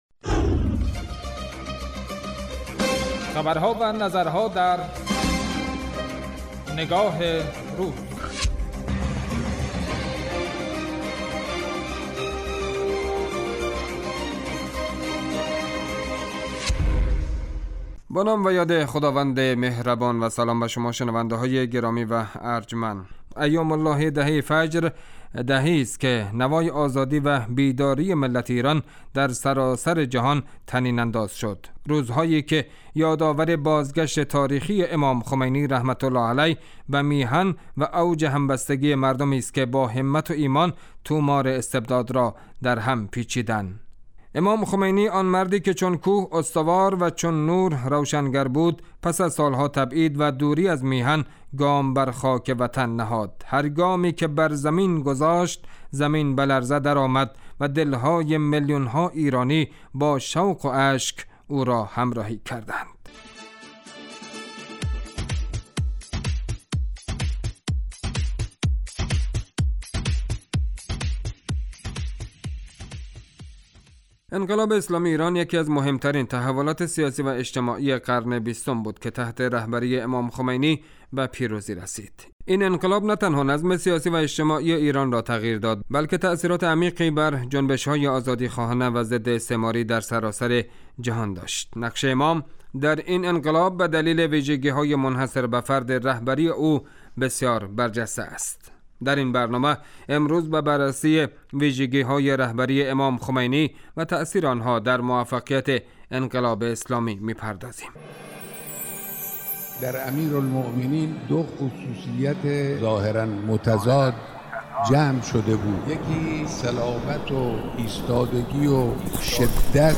اطلاع رسانی و تحلیل و تبیین رویدادها و مناسبتهای مهم ، رویکرد اصلی برنامه نگاه روز است .